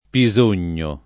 vai all'elenco alfabetico delle voci ingrandisci il carattere 100% rimpicciolisci il carattere stampa invia tramite posta elettronica codividi su Facebook Pisogno [ pi @1 n’n’o ] top. (Piem.) — pn. loc. con -o- aperto